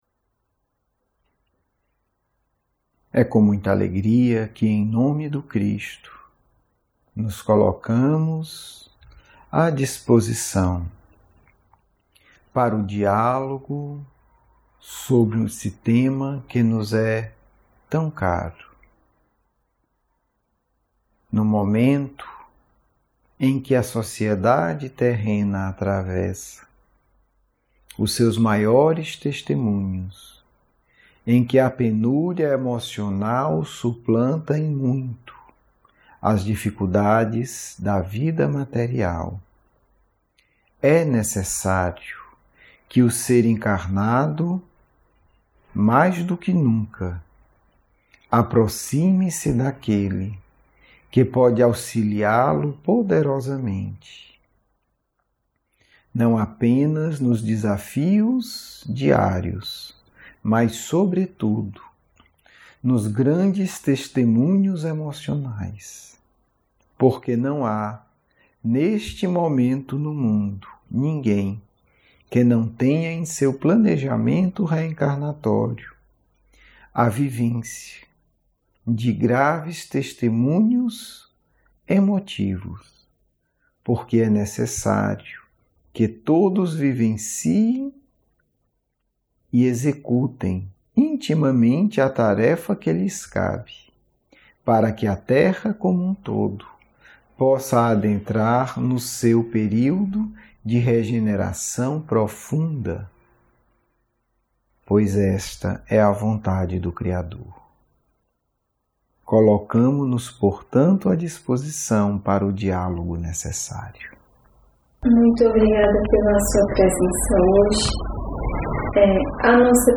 Aula 5 - Diálogo mediúnico - 1